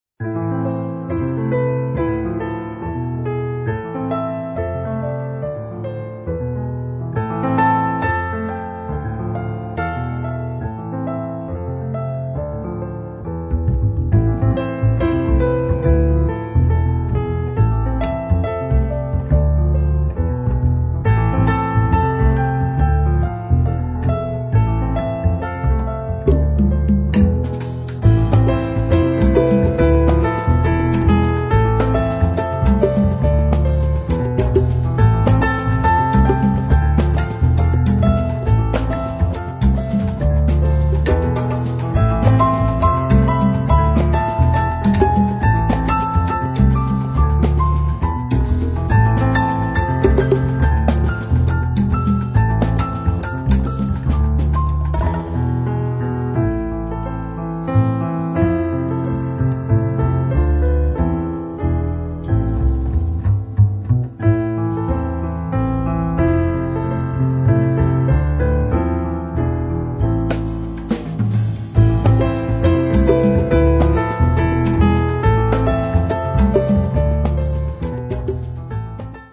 メロウで甘口、ジャーマン・ジャズ・ピアノ・トリオの2013年2ndアルバム